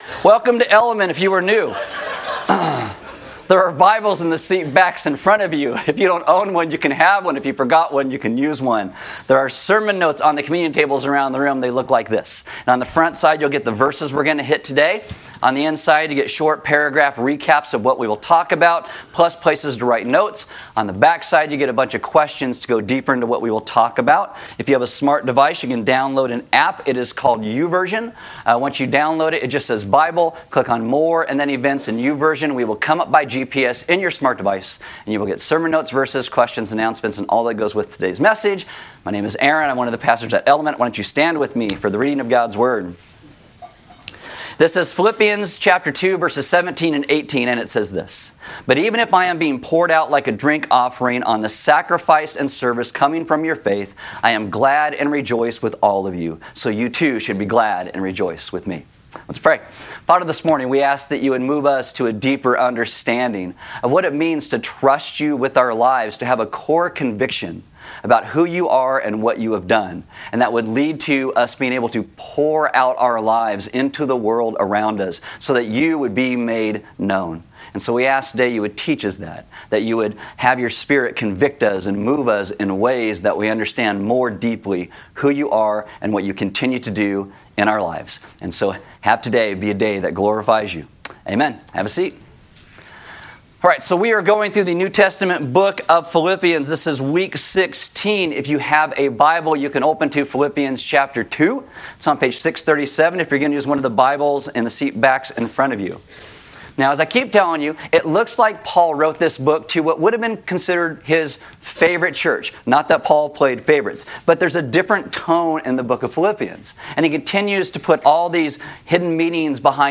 A message from the series "The Minors." This week we look at the minor prophet: Zechariah.